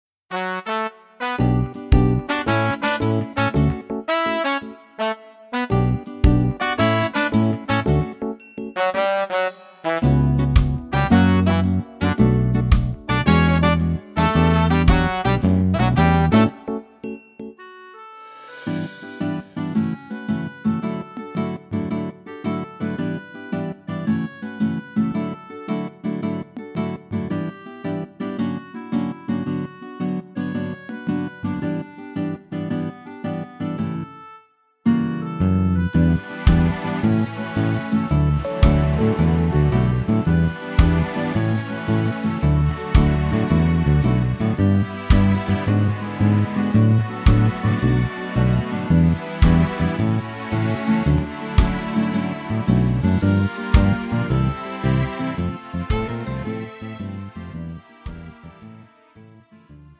장르 가요 구분 Pro MR